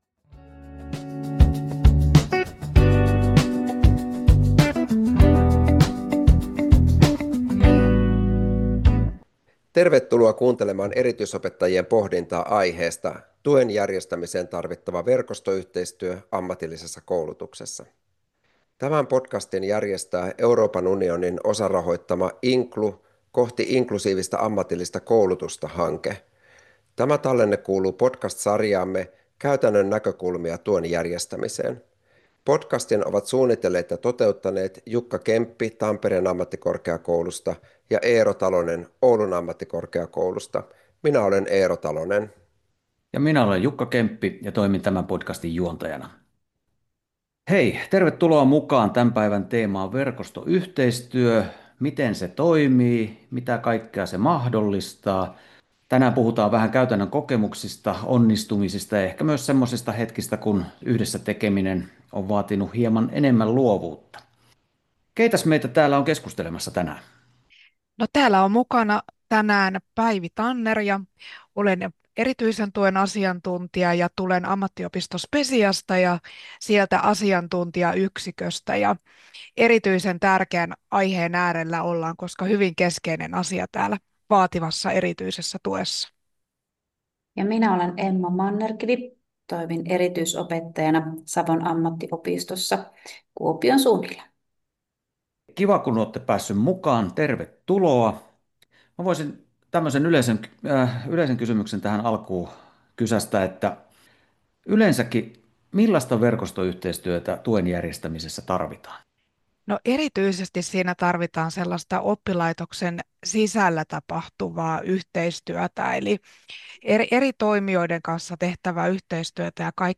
Aihetta käsittelevät neljä erityisopetuksen asiantuntijaa